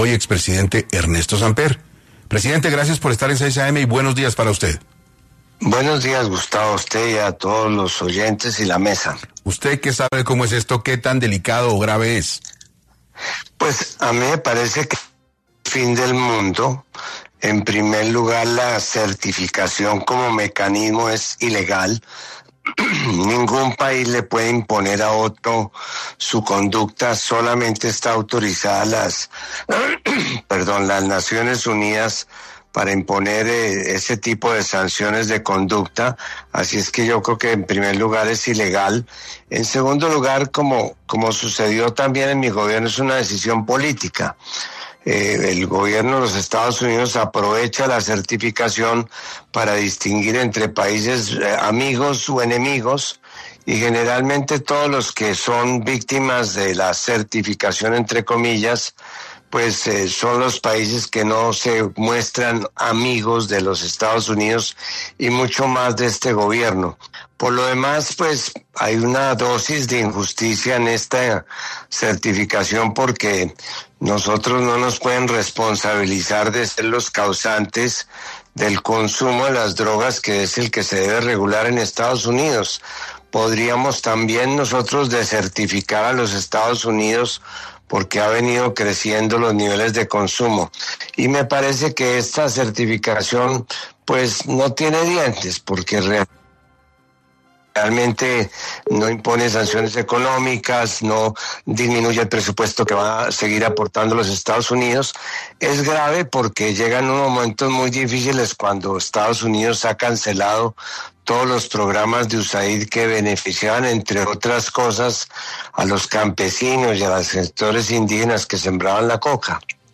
En una entrevista a 6AM de Caracol Radio, Samper argumentó que ningún país puede imponer sanciones unilaterales a otro y que esta decisión responde a motivos políticos.